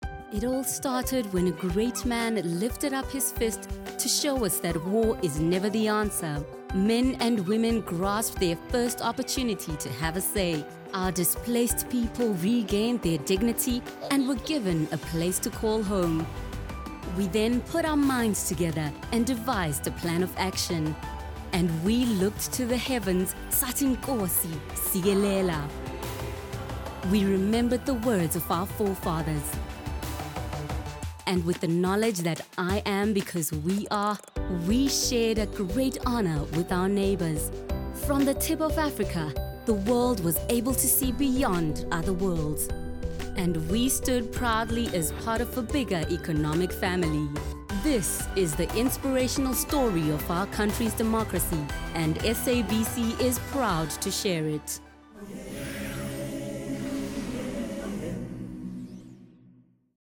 animation, articulate, bright, commercial, confident, conversational, friendly, High Energy, Trendy